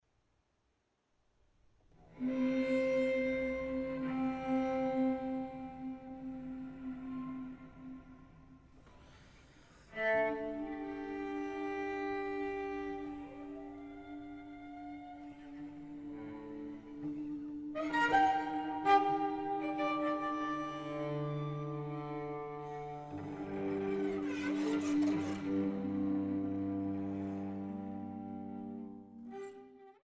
Violoncello und Arrangements